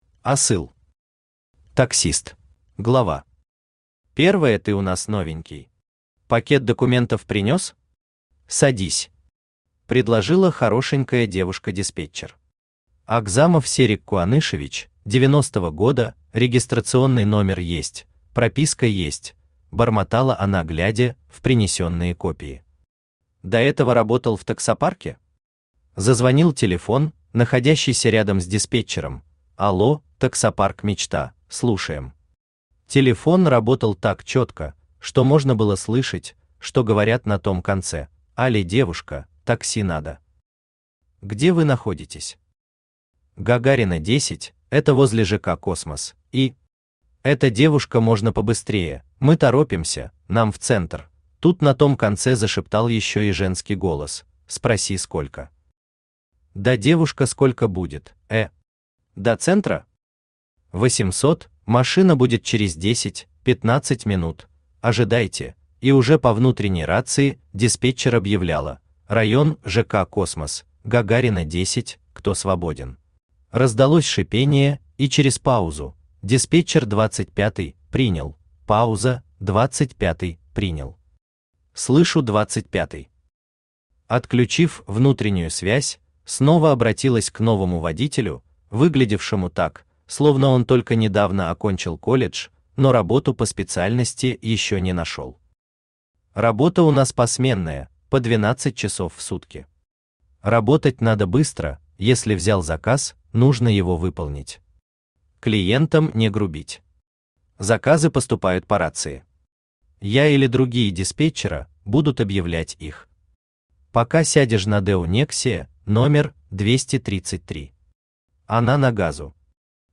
Аудиокнига Таксист | Библиотека аудиокниг
Aудиокнига Таксист Автор Асыл Читает аудиокнигу Авточтец ЛитРес.